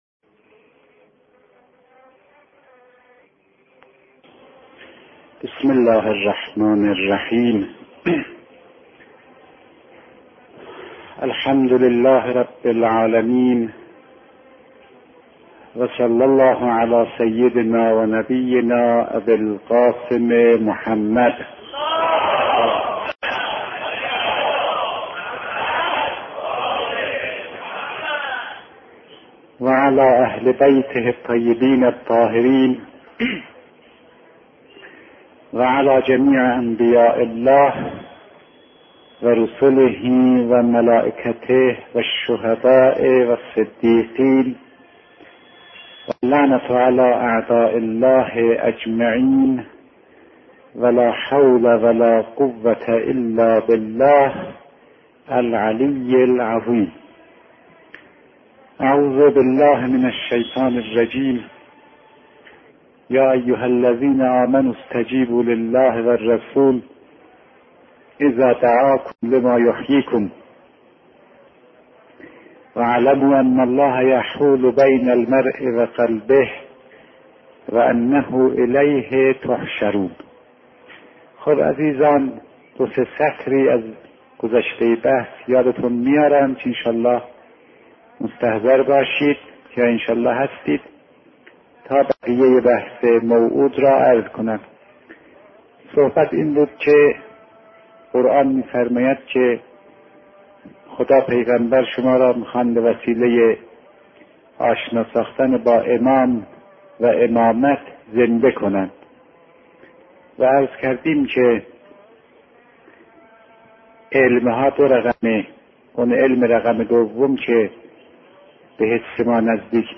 بیانات عارف بزرگوار حضرت آیت الله فاطمی نیا، پیرامون مباحث اخلاق در قرآن با عنوان «عرفان عملی و سلوکی» / مدت زمان : 59 دقیقه